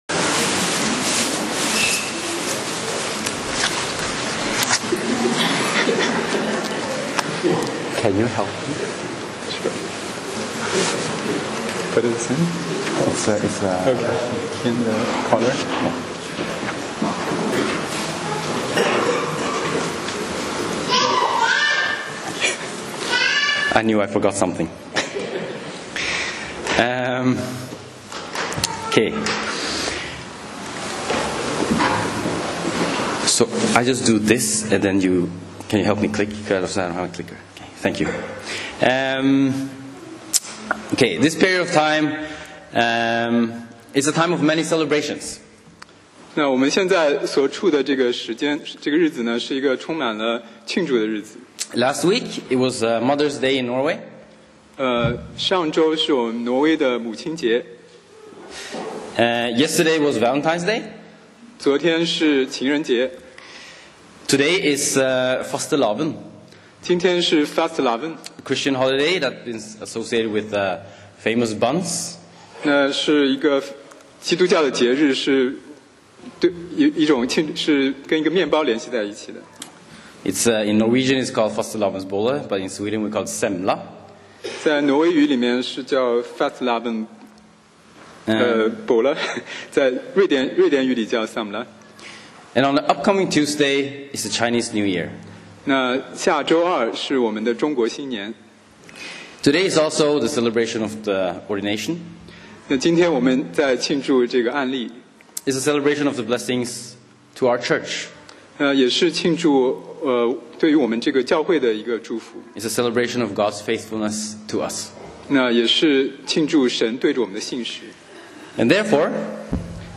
講道 Sermon 題目 Topic：Next to 其次是 經文 Verses：尼希米記3章 1那時、大祭司以利亞實、和他的弟兄眾祭司、起來建立羊門、分別為聖、安立門扇、又築城牆到哈米亞樓、直到哈楠業樓、分別為聖。